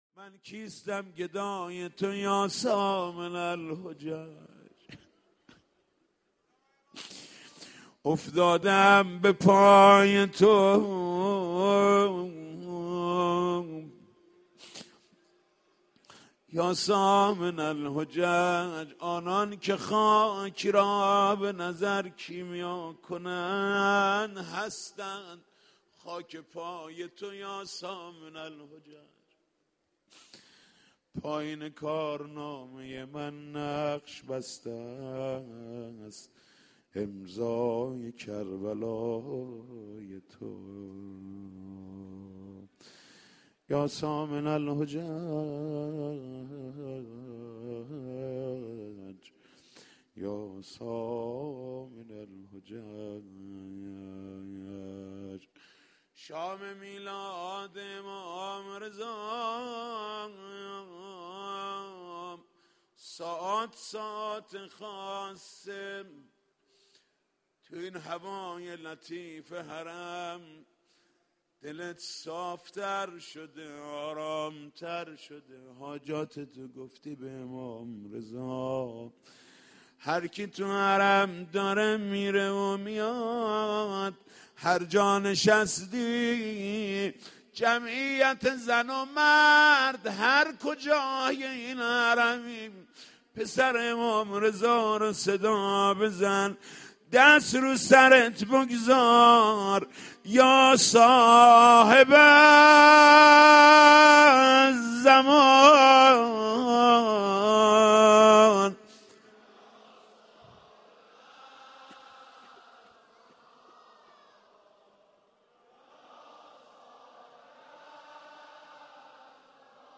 این مراسم در حرم امام رضا(ع) صحن جامع رضوی برگزار گردید
عقیق:صوت این جلسه را بشنوید بخش اول - شعرخوانی ( در ظل روزگار شما یا ابالحسن ) [حاج محمود کریمی] بخش دوم - زمـزمه ( اسمت برام تسکینه ) [حاج محمود کریمی] بخش سوم - مناجات ( اسمت برام تسکینه ) [حاج محمود کریمی]